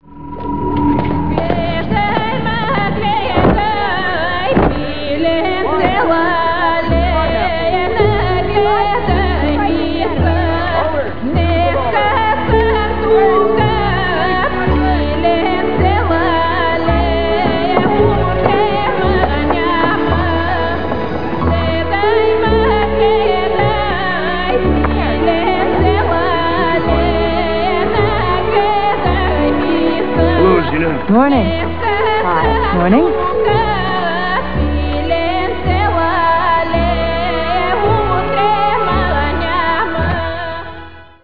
Funky solo-